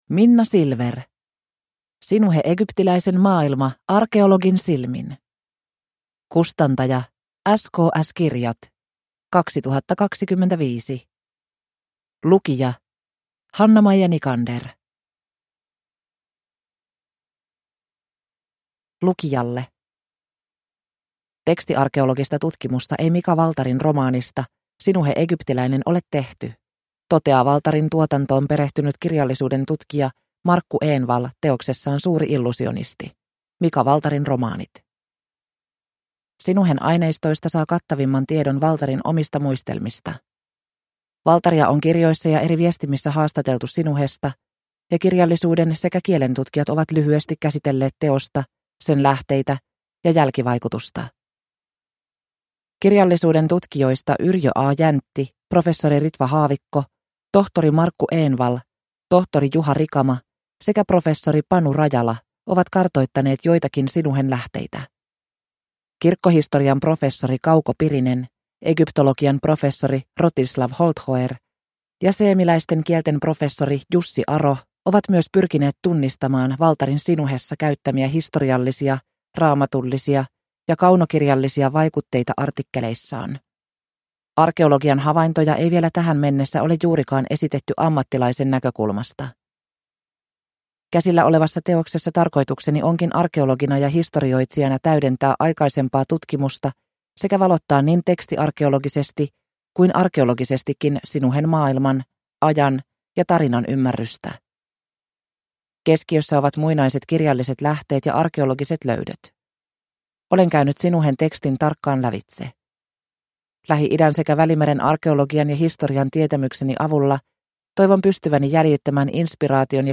Sinuhe egyptiläisen maailma – Ljudbok